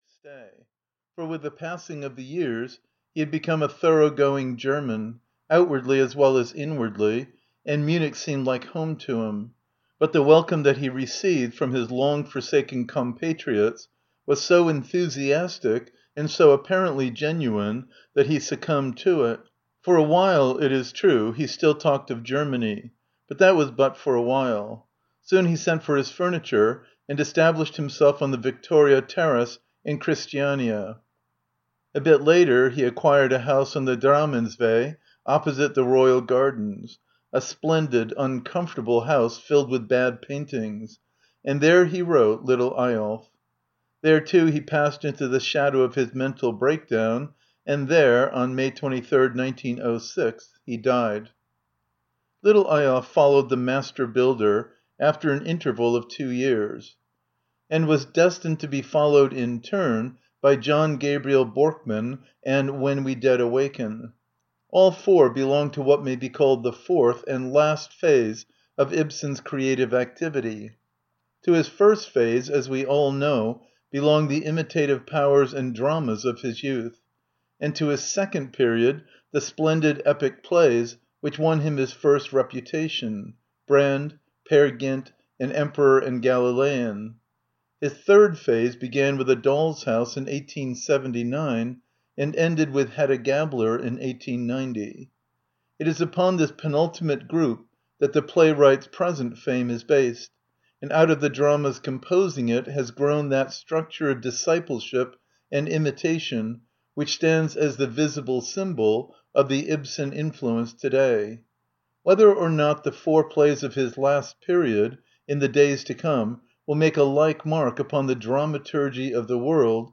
Audio knihaLittle Eyolf (EN)
Ukázka z knihy